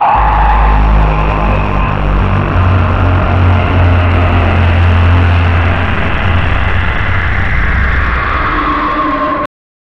A heavy silence for a moment, then a slow, hissing breath, almost a sigh of deep, unnatural contentment.